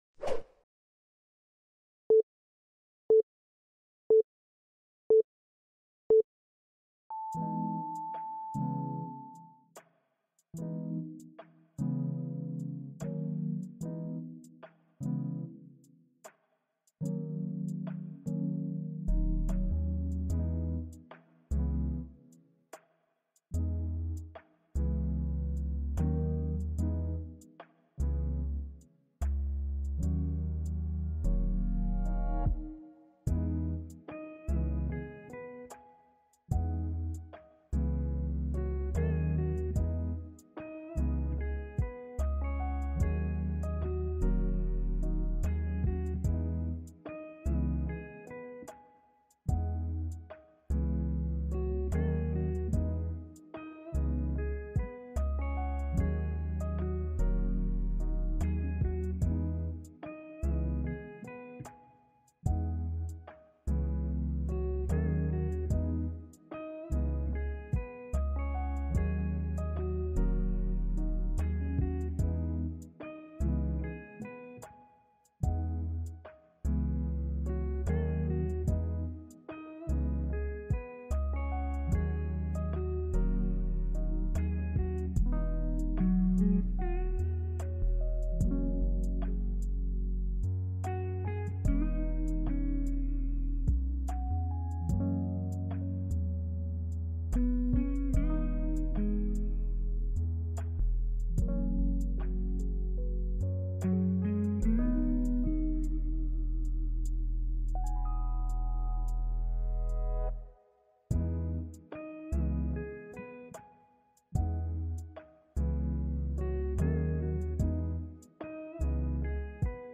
Ambiance Victorienne : Pluie Concentrée
Aucun bruit parasite, aucune coupure soudaine.